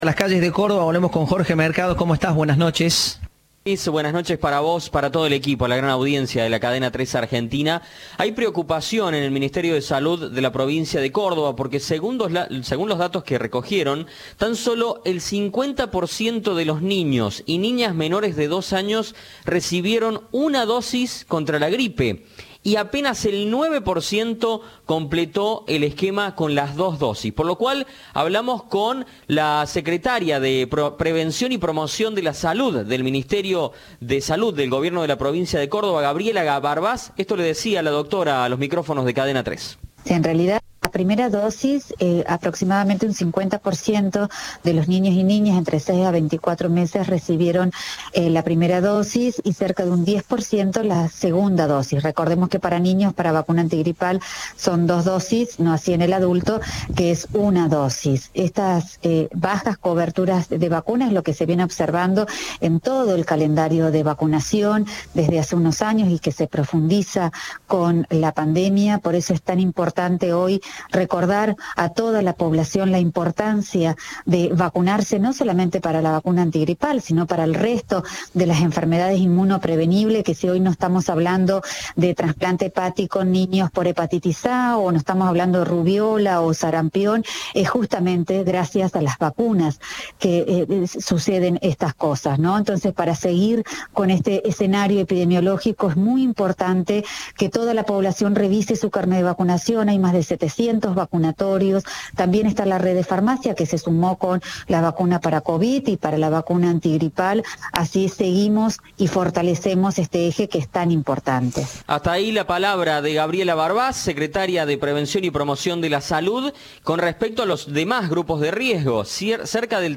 La secretaria de Salud provincial, Gabriela Barbás, dijo a Cadena 3 que se observa una baja desde hace algunos años que se profundizó con la pandemia.